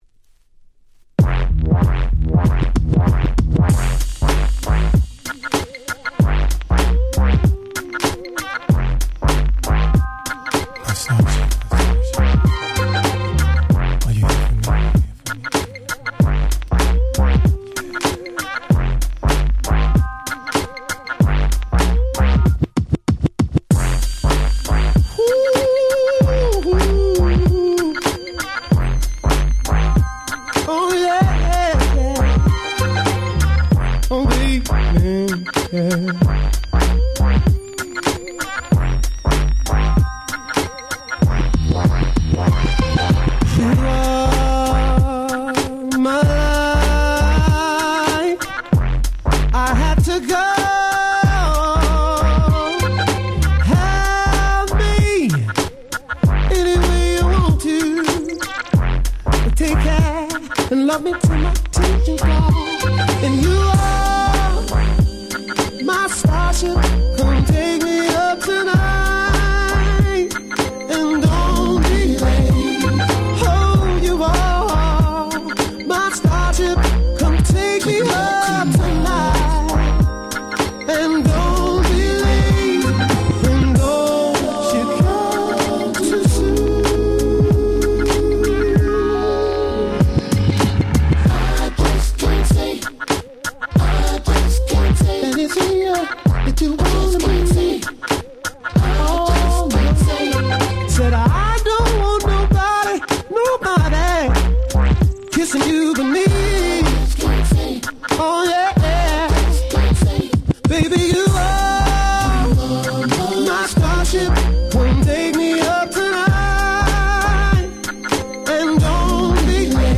※一部試聴ファイルは別の盤から録音してございます。
ヤナ ダズバンド 00's キャッチー系